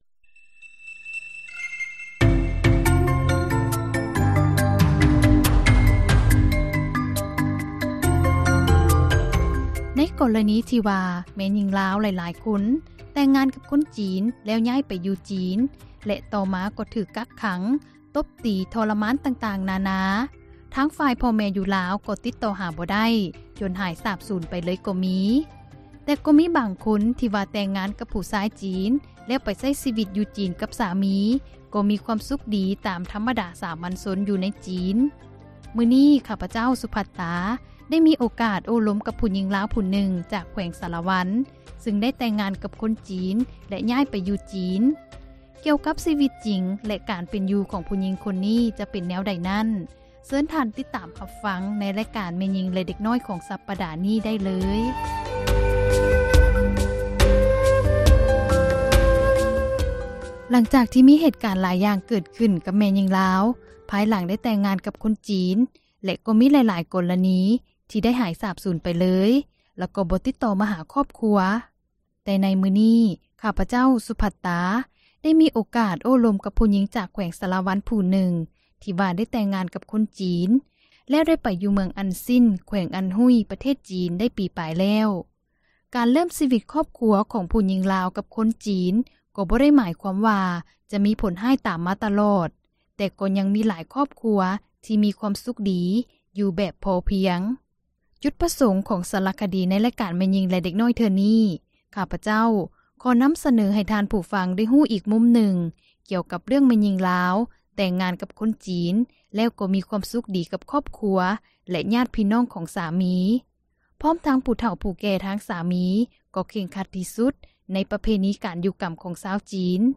ໄດ້ມີໂອກາດໂອ້ລົມກັບ ແມ່ຍິງລາວຜູ້ນຶ່ງ